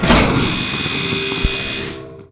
pump.wav